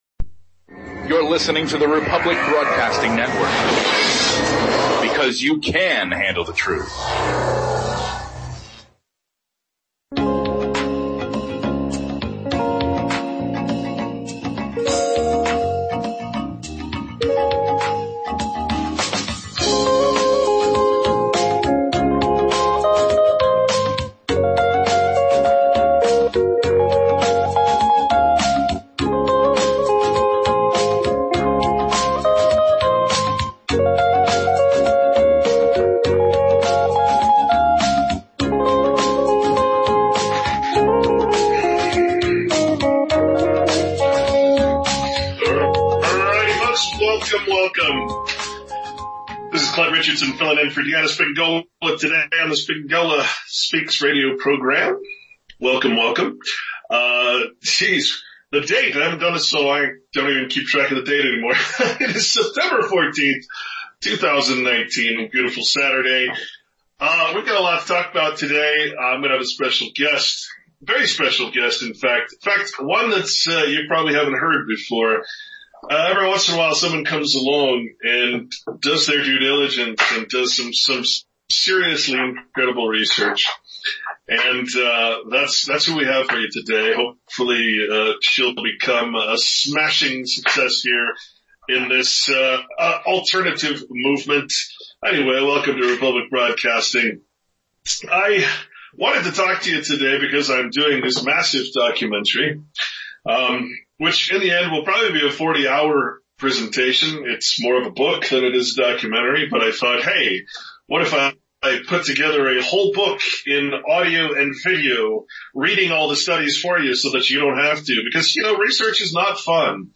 It appears this was going to be a fabulous topic, but the guest was not clear – due to her hell-phone connection. It should be standard that when people present information through audio, hell-phones need to be avoided..they are a terrorizing format and the audio is grating, distorted and made me feel vertigo.